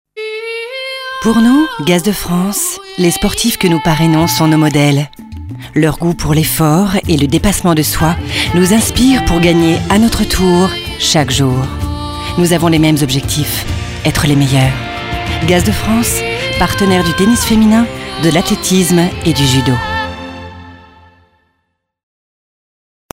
Genre Publicité en voix-off
Timbre : Chaud Posé Rassurant Souriant